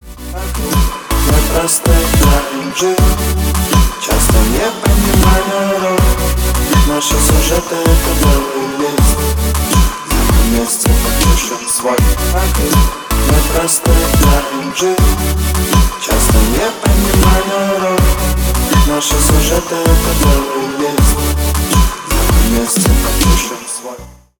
Ремикс # Поп Музыка
грустные